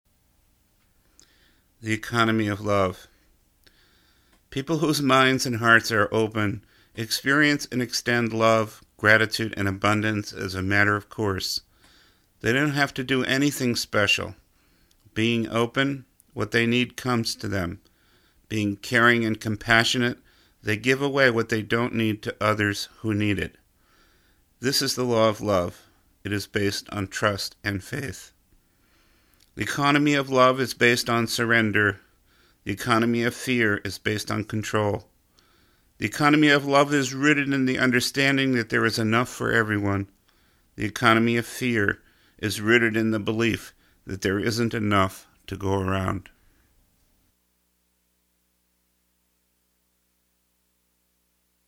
Book Excerpt